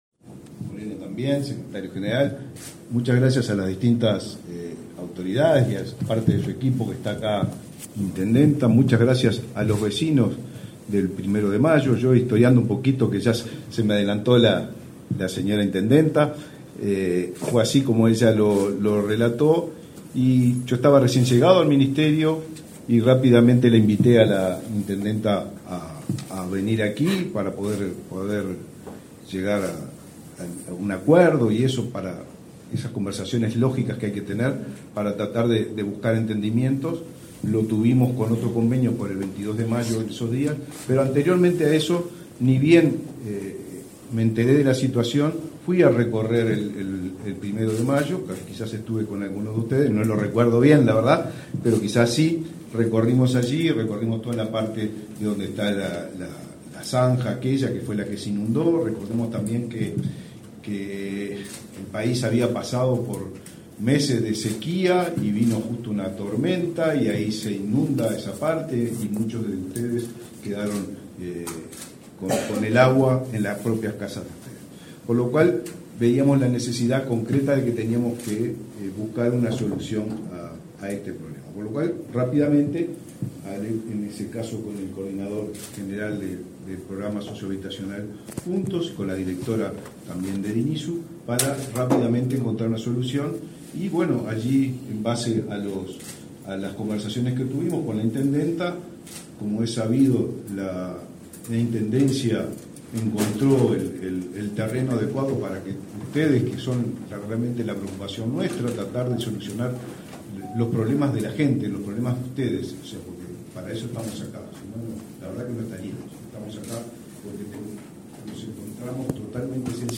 Palabras del titular del MVOT, Raúl Lozano
Disertó en el evento el titular de la cartera, Raúl Lozano.